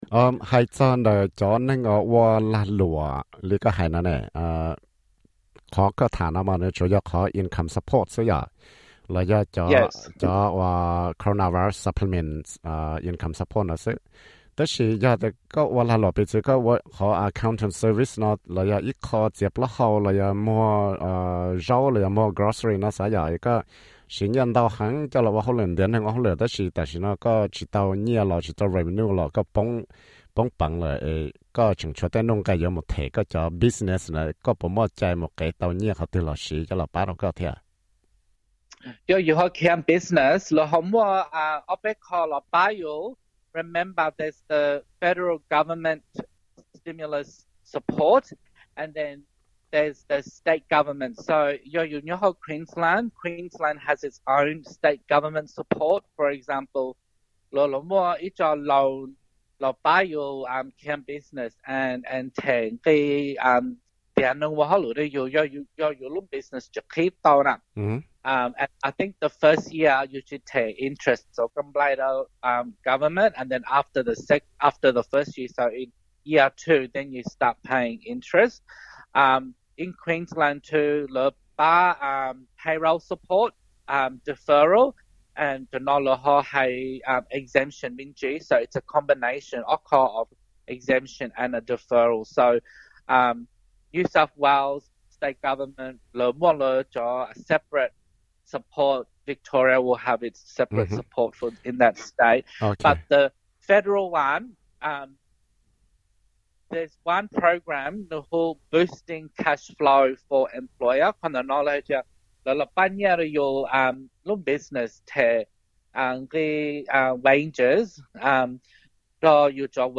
Disclaimer: The insights from this interview in regards to the Australian government economic stimulus package during COVID-19 pandemic is for general information only.